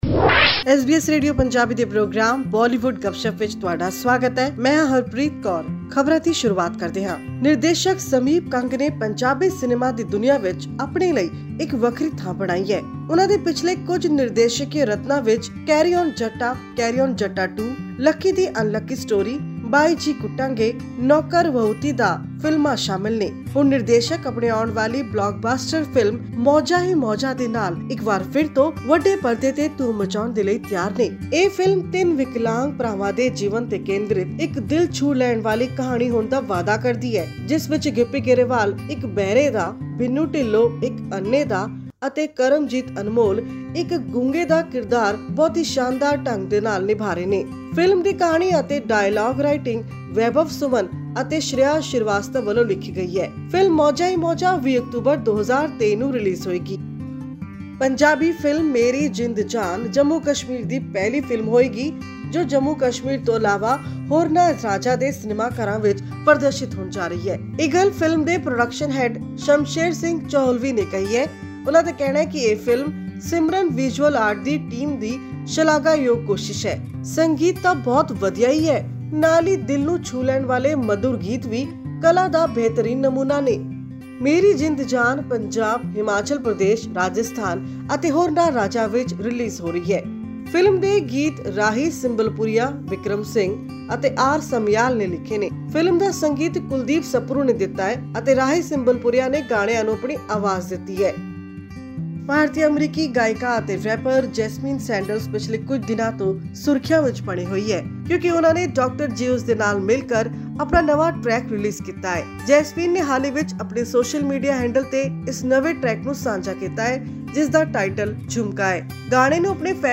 The Punjabi comedy movie 'Maujaan Hi Maujaan,' starring Gippy Grewal, Binnu Dhillon, and Kamamjit Anmol, revolves around a trio of characters who are deaf, dumb, and blind. This and more in our weekly news segment of Bollywood Gupshup on upcoming movies and songs.